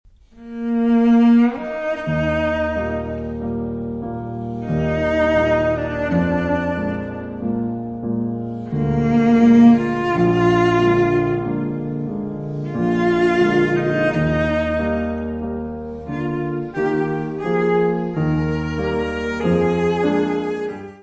trios for clarinet, cello and piano
Music Category/Genre:  Classical Music
Adagio.